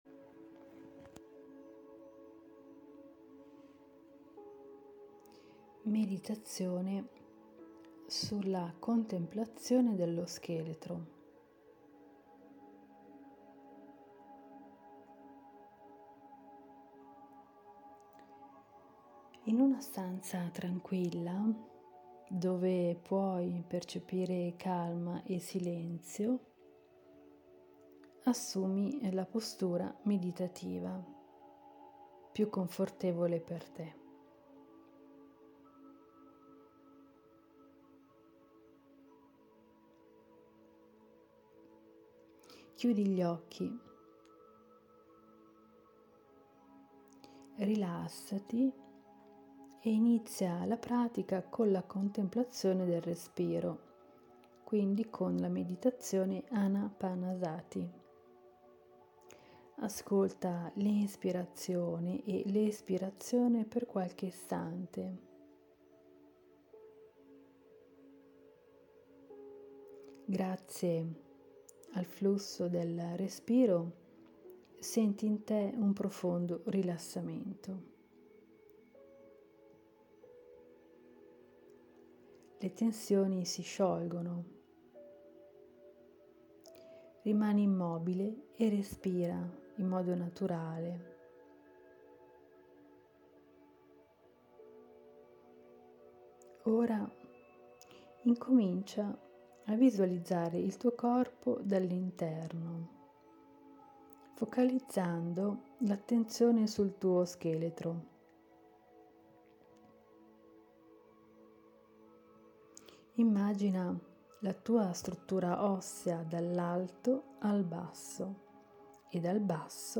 La Meditazione sulla contemplazione dello scheletro e il potere delle ossa